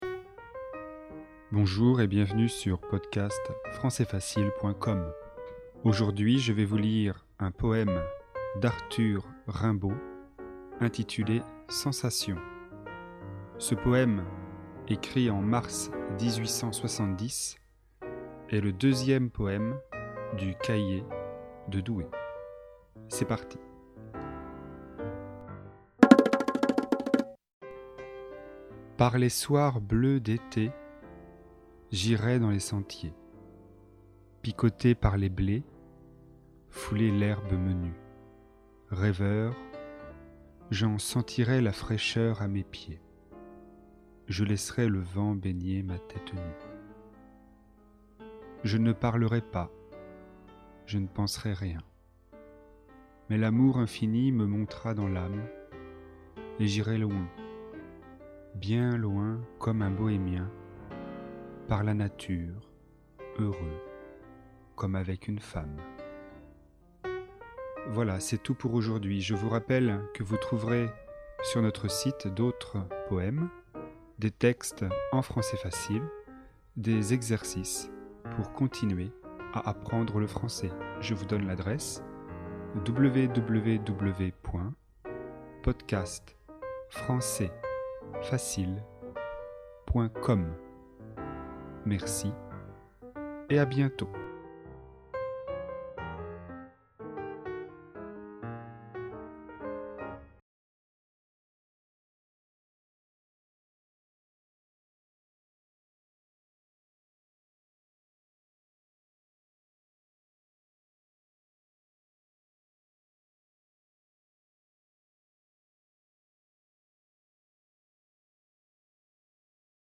Poésie, niveau avancé (C1).